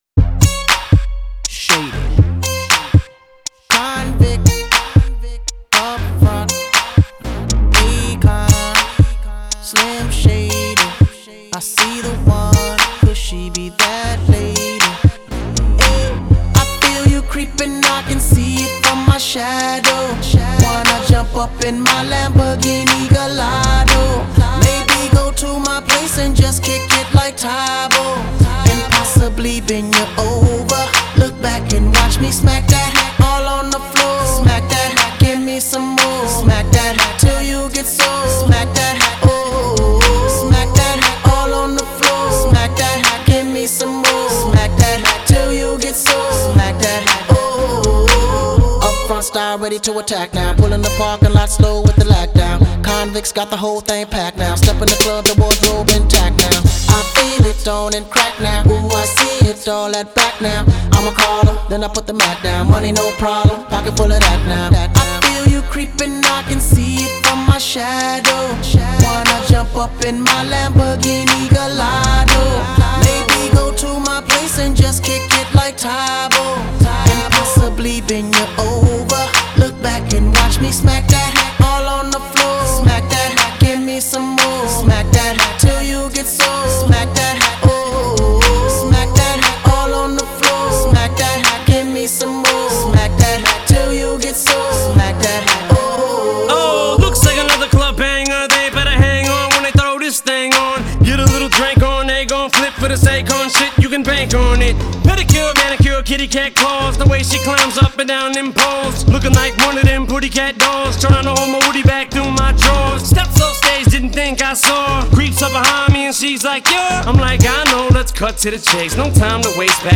Зарубежный Рэп